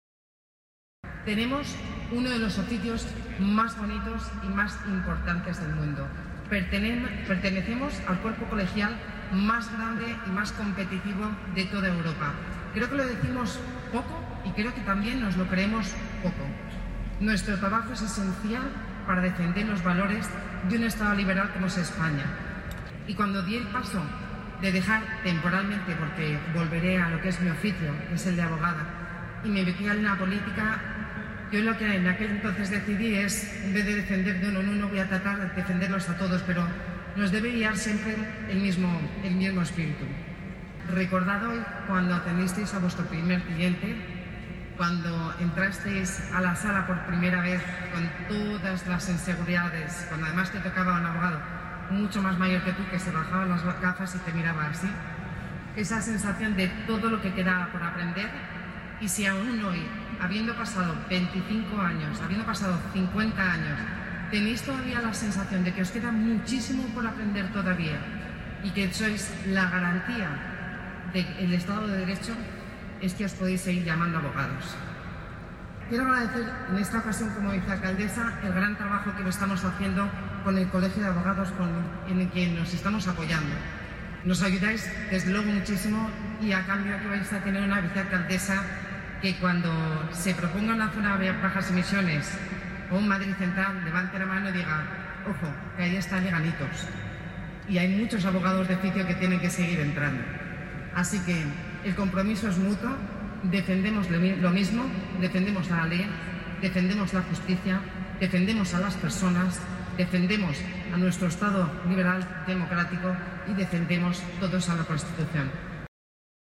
En la ceremonia de entrega de diplomas e insignias a los abogados que cumplen 25 y 50 años colegiados en el Ilustre Colegio de Abogados de Madrid
Nueva ventana:Begoña Villacís, vicealcaldesa de Madrid